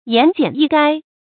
注音：ㄧㄢˊ ㄐㄧㄢˇ ㄧˋ ㄍㄞ
言簡意賅的讀法